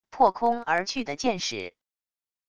破空而去的箭矢wav音频